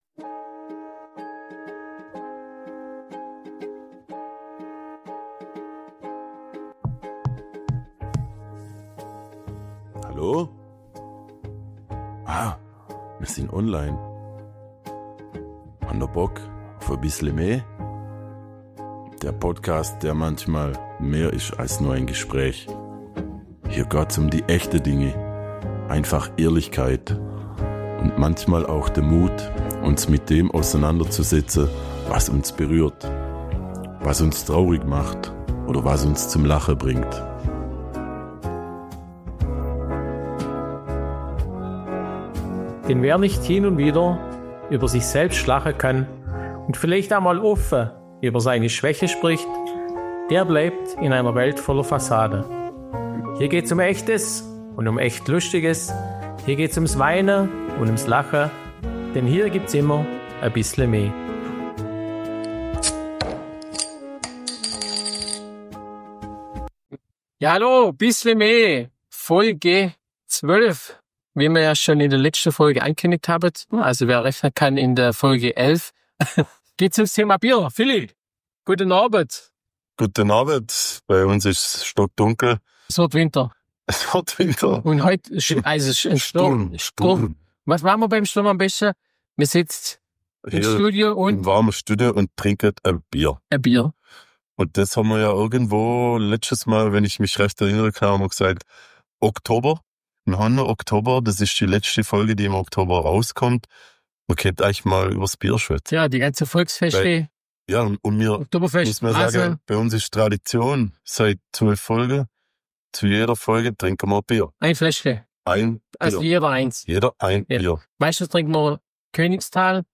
#12 Schaum vorm Mund – alles über Bier! ~ Bissle me – Schwoba-Podcast aus´m Schlofsack Podcast